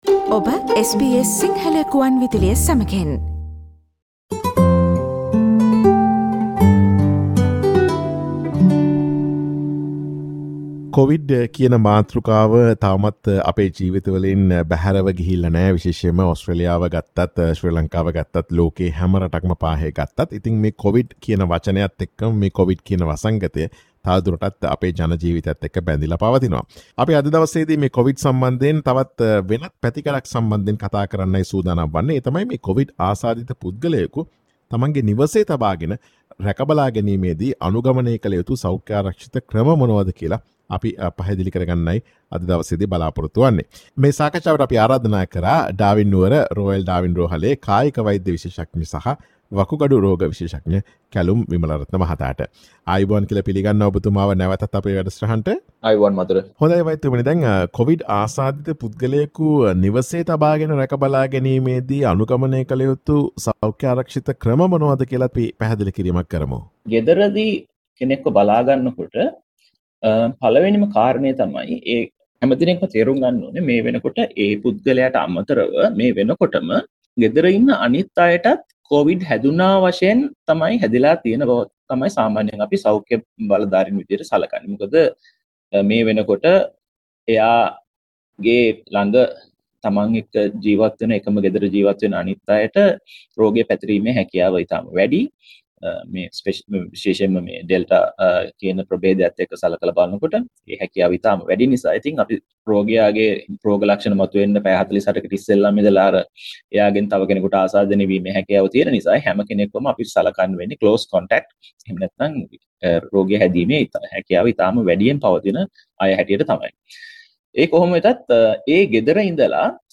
කොවිඩ් ආසාදිත පුද්ගලයෙකු නිවසේ සිට රැක බලා ගැනීමේදී අනුගමනය කළ යුතු සෞඛ්‍ය ආරක්‍ෂිත ක්‍රම පිළිබඳ SBS සිංහල ගුවන් විදුලිය සිදුකළ සාකච්ඡාවට සවන් දෙන්න.